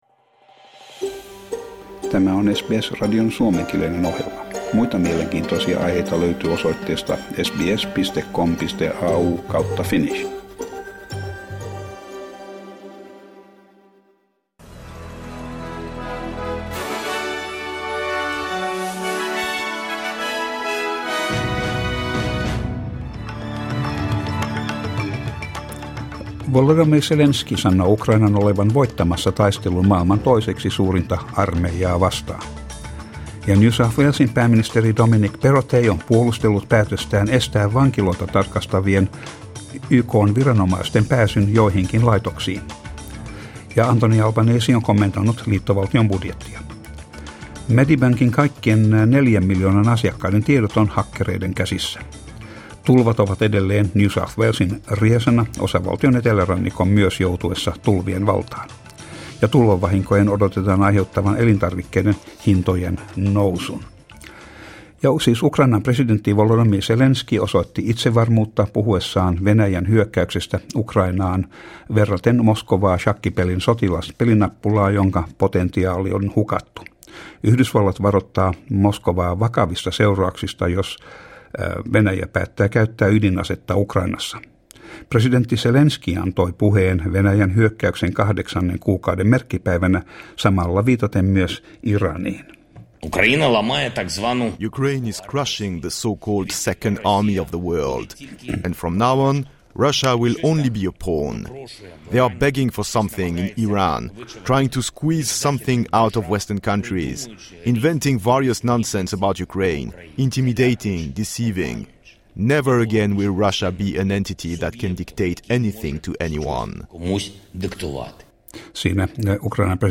Uutiset ja sää 26.10.22